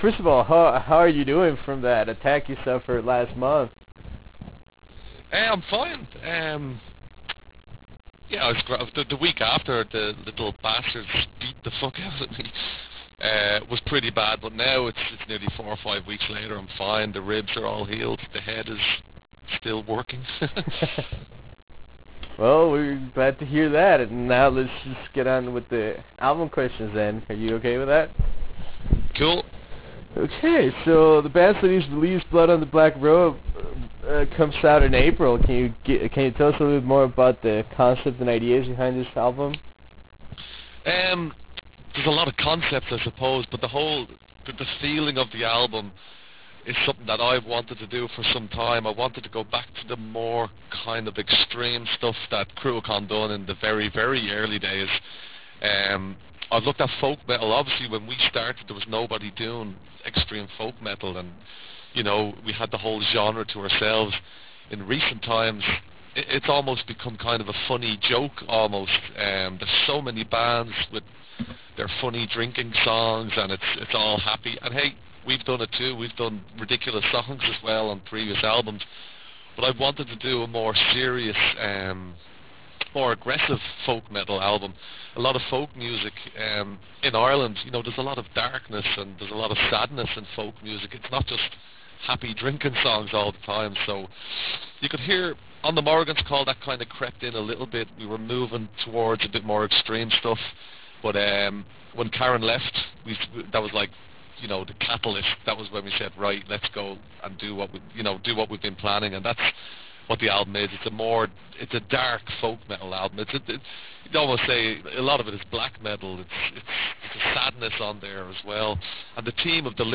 Interview with Cruachan